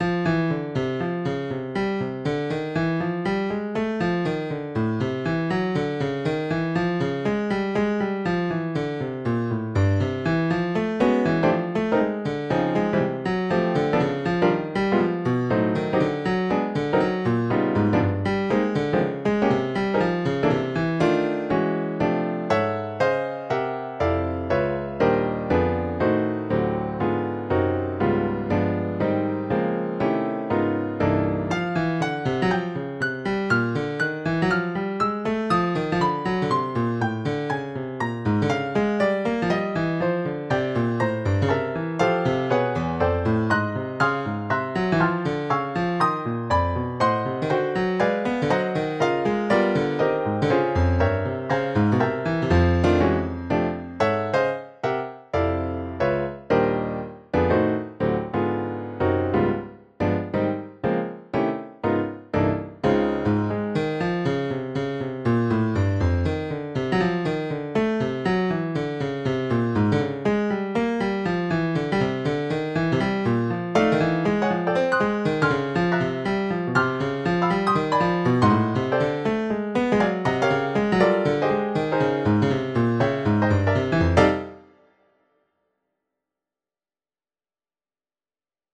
Recently I decided I wanted to get decent recordings of my thesis compositions, so I have been recording them using GarageBand.
Variation 1 is a retrograde inversion (the melody upside-down and backwards); variation 2 is a straightforward reharmonization; variation 3 is the melody in the form of a blues; variation 4 is an augmentation (one note of the melody is used in each measure of the variation's melody); variation 5 is a more interesting reharmonization.